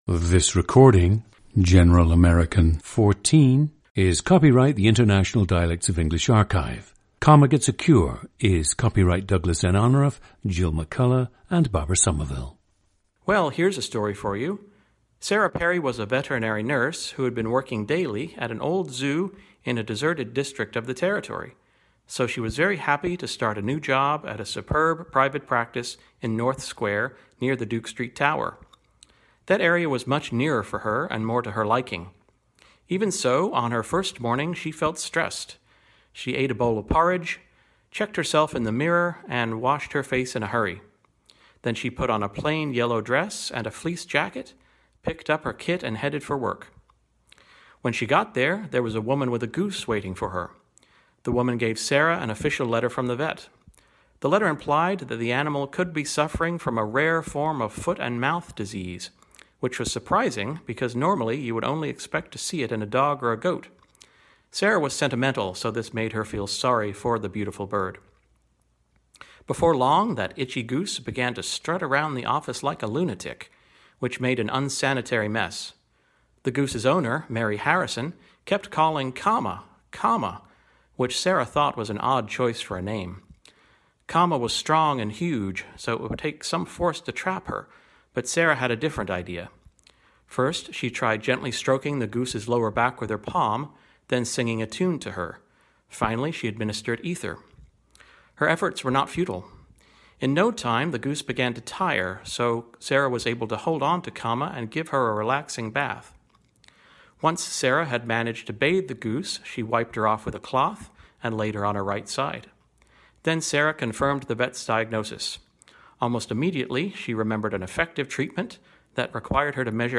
General American 14
generalamerican-14.mp3